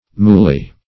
Moolley \Mool"ley\, n.